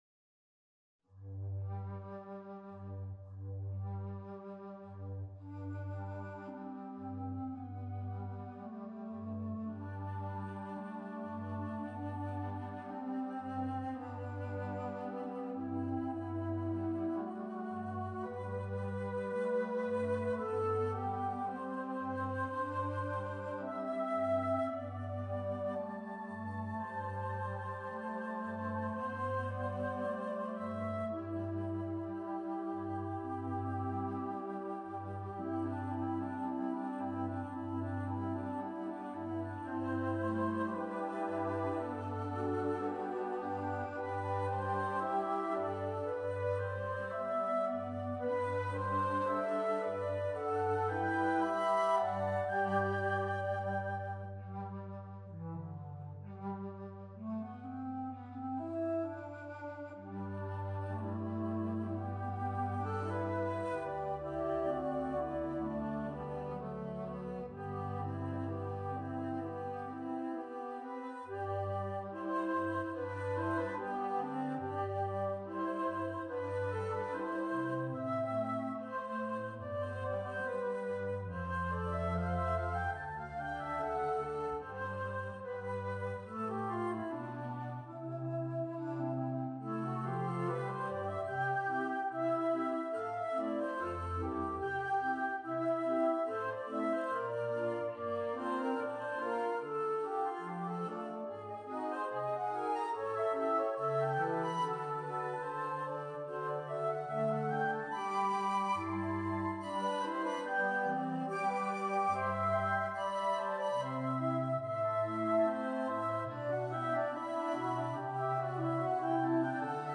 Six-part flute choir [three alto flutes, two bass flutes, contrabass flute] (2025)